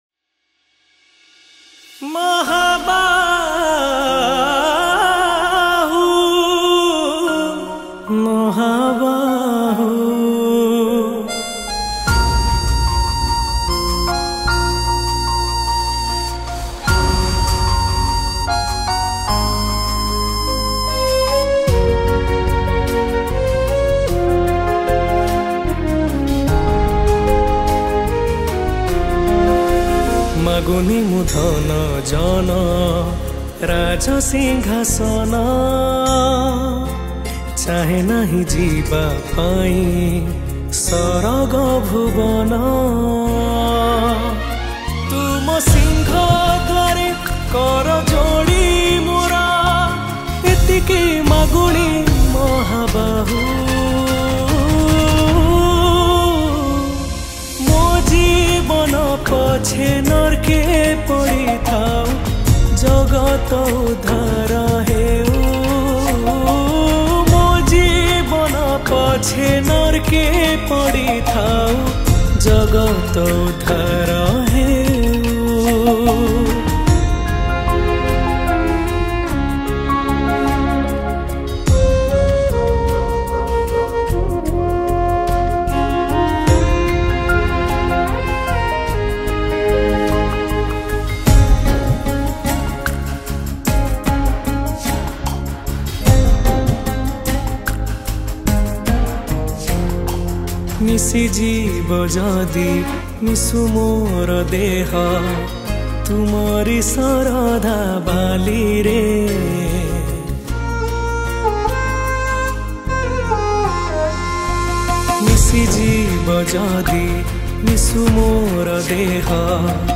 Odia Bhajan Song 2025